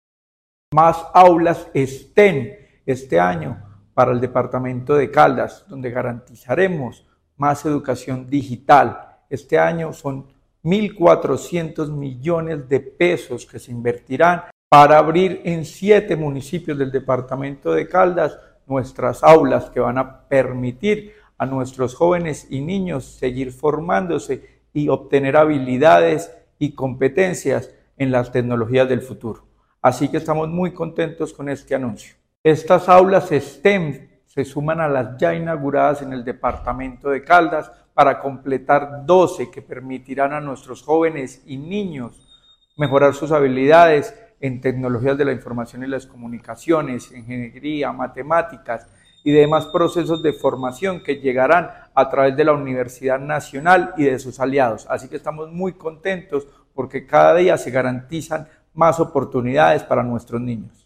Secretario de Educación de Caldas, Luis Herney Vargas Barrera.
Secretario-de-Educacion-Luis-Herney-Vargas-aulas-STEM-nuevas.mp3